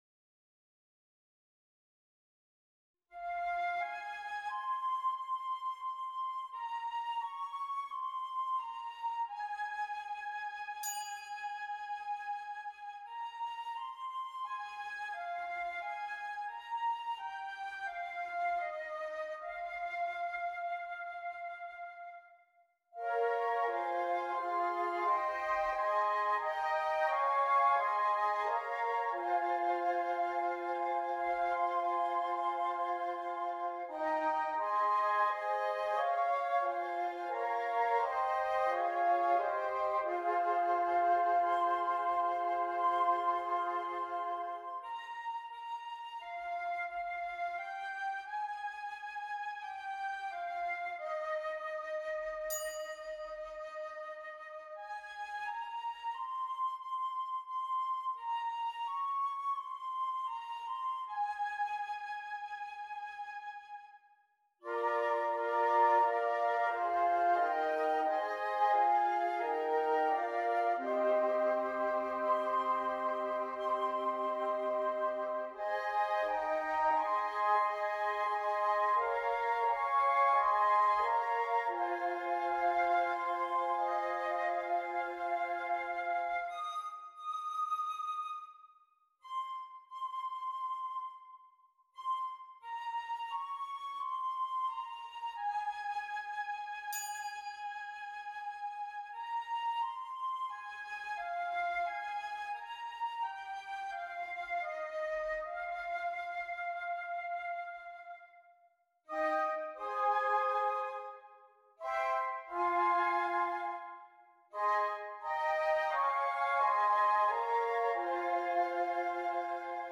5 Flutes
Traditional